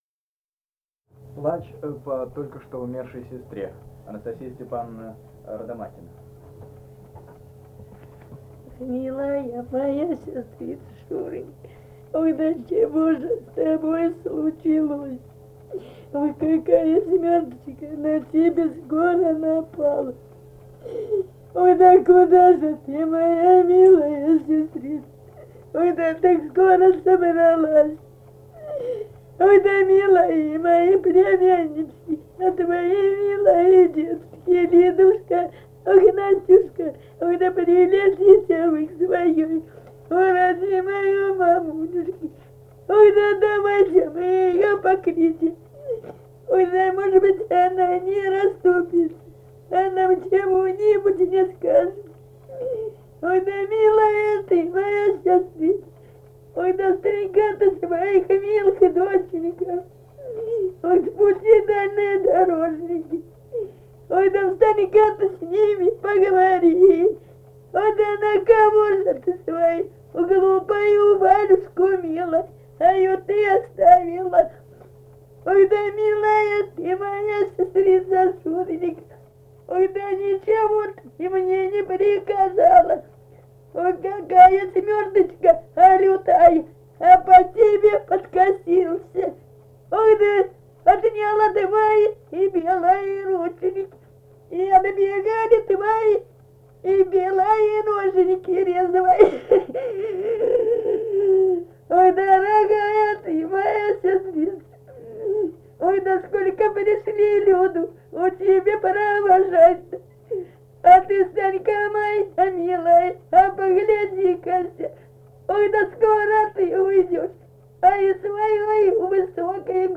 Этномузыкологические исследования и полевые материалы
Самарская область, с. Кураповка Богатовского района, 1972 г. И1318-21